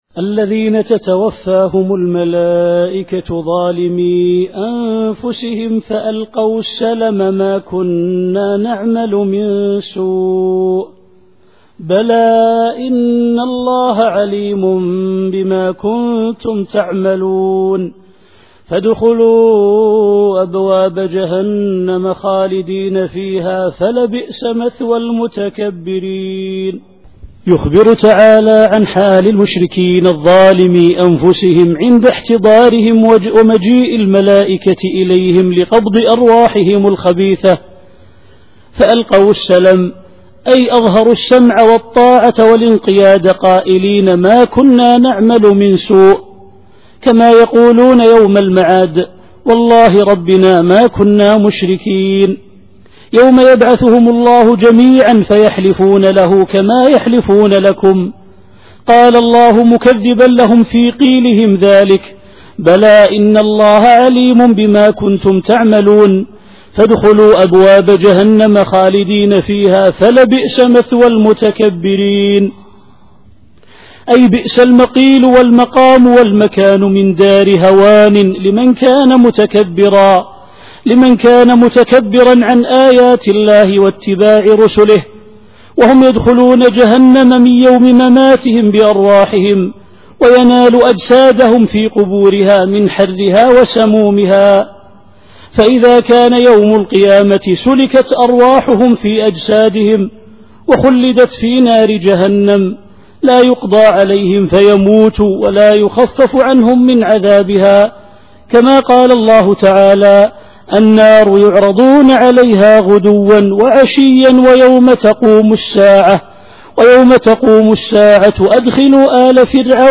التفسير الصوتي [النحل / 28]